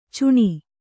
♪ cuṇi